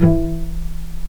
healing-soundscapes/Sound Banks/HSS_OP_Pack/Strings/cello/pizz/vc_pz-E3-pp.AIF at bf8b0d83acd083cad68aa8590bc4568aa0baec05
vc_pz-E3-pp.AIF